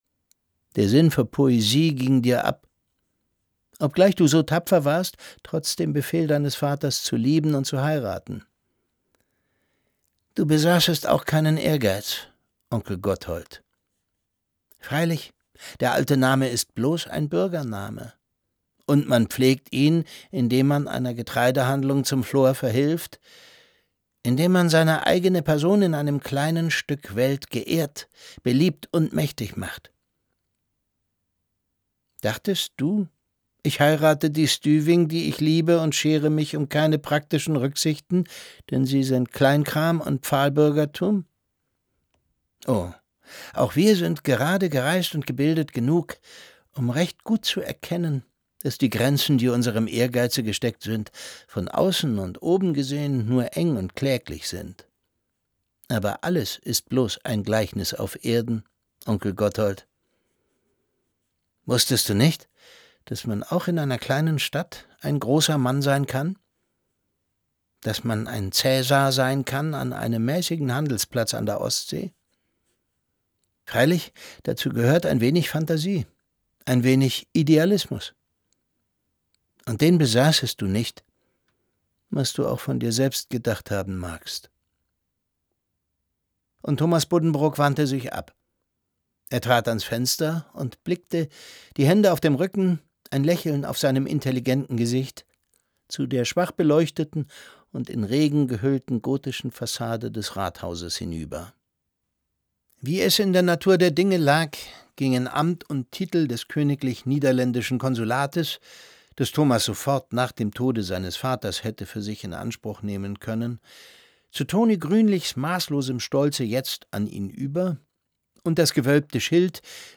Jetzt steht er am Totenbett des Onkels und reflektiert dessen Leben: Fantasie hat ihm gefehlt, denkt er, Idealismus und Enthusiasmus… Es liest Thomas Sarbacher.